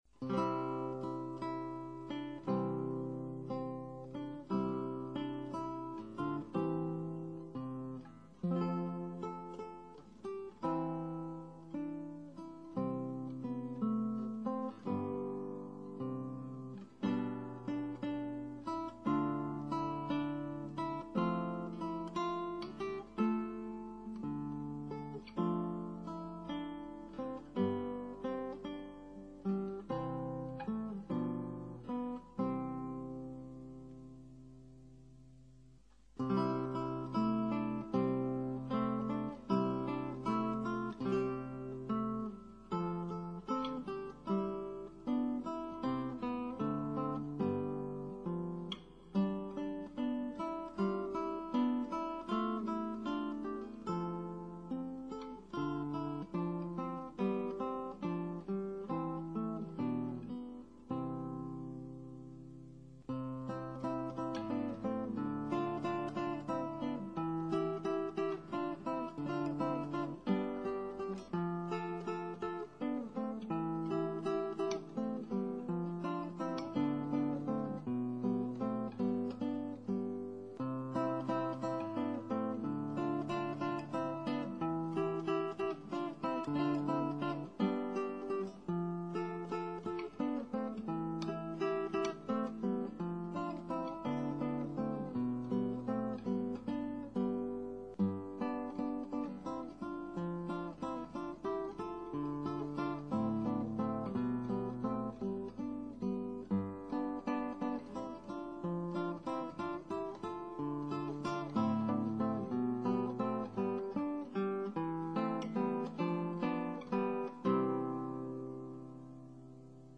Original Works for Classical Guitar